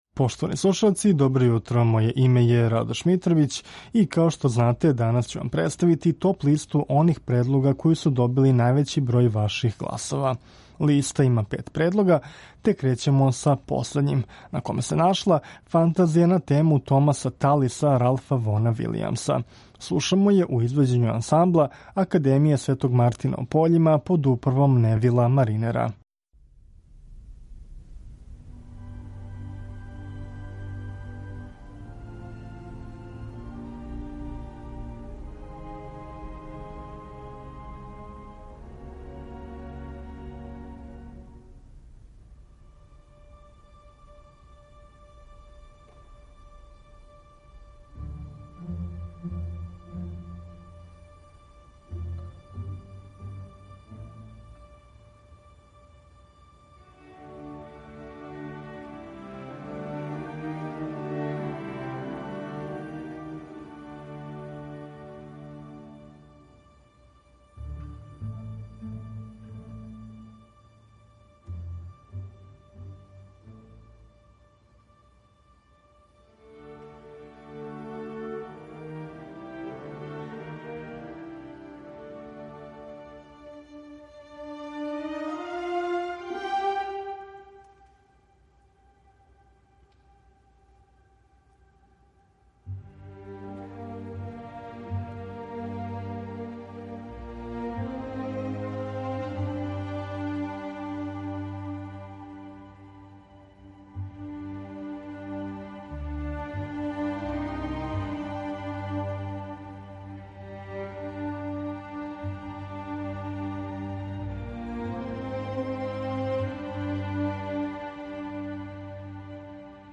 У овонедељној емисији Класику, молим наша окосница биће дела за харфу.